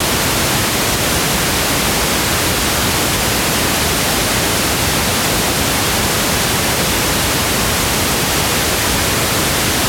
rosa Rauschen - LD Systems MEI 1000 G2
FUNK
Da ich den Unterschied zwischen Funk und kabelgebundenen Systemen aber genau wissen wollte, habe ich jedes System mit rosa Rauschen beschickt und den Kopfhörerausgang wieder aufgenommen.
Der größte Unterschied zeigt sich vor allem im beschnittenen Hochtonbereich beim Funk-Signal. Hier wird bereits ab ca. 12kHz bedämpft.
Auch die Bässe gehen etwas verloren.
rosa-rauschen-LD-Systems-MEI-1000-G2.wav